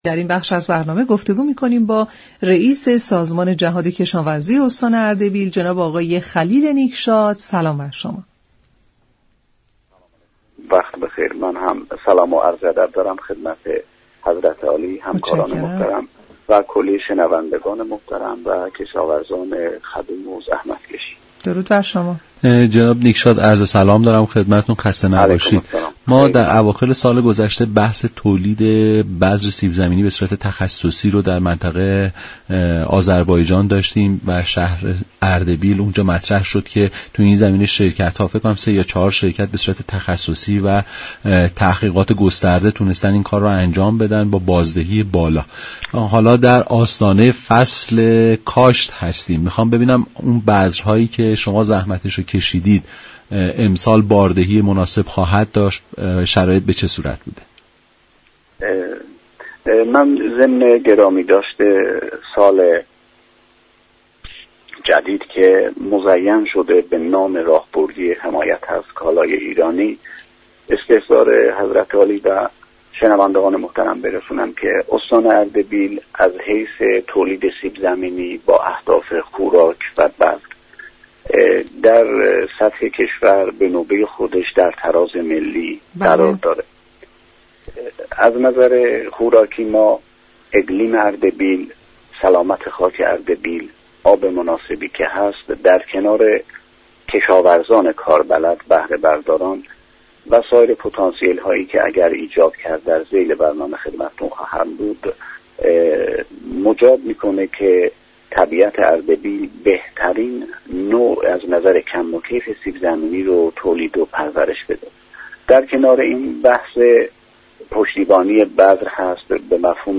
«خلیل نیكشاد» رییس سازمان جهاد كشاورزی استان اردبیل در برنامه «نمودار»، به شرح جزئیات تولید بذر سیب زمینی در منطقه آذربایجان و اردبیل پرداخت.